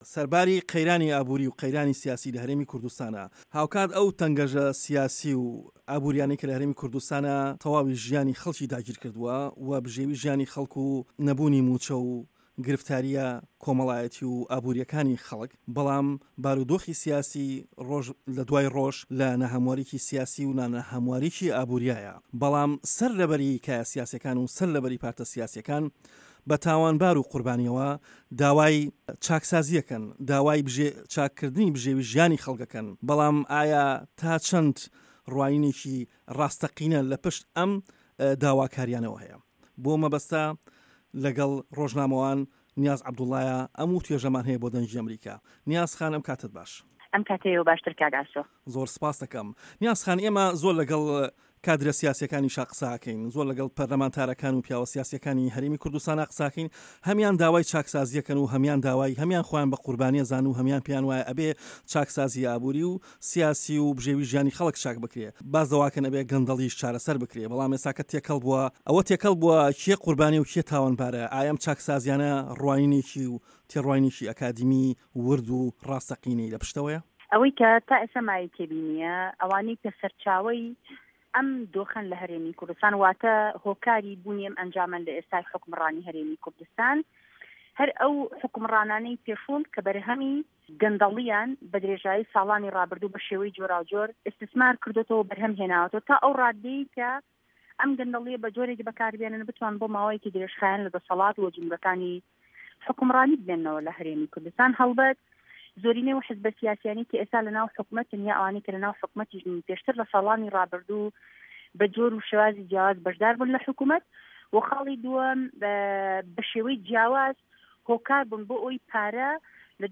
وتووێژی نیاز عه‌بدوڵا